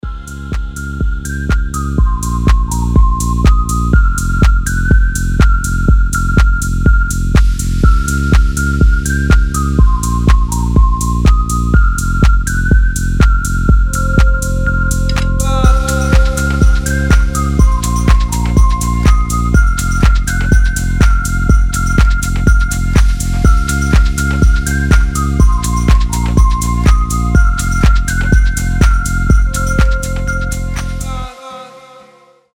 Electronic
без слов
приятные
Флейта
легкие
дудка
Лёгкая музыка, заряженная на привлечение тепла